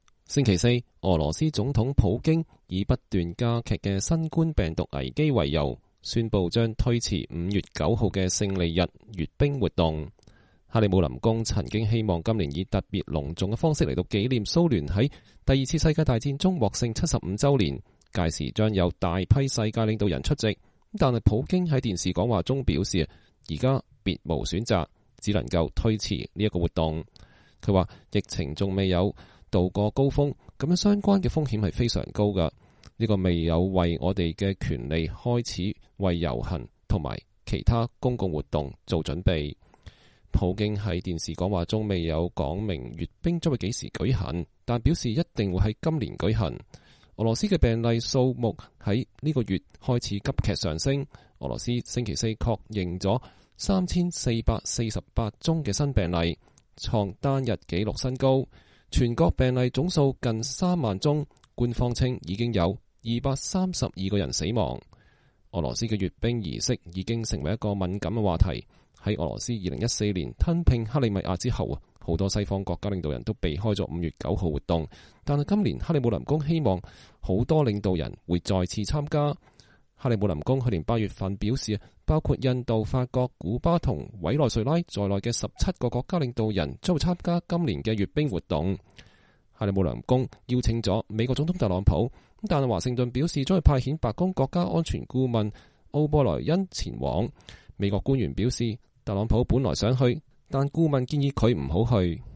克林姆林宮曾希望今年以特別隆重的方式來紀念蘇聯在二戰中獲勝75週年，但普京在電視講話中表示，現在別無選擇，只能推遲這一活動。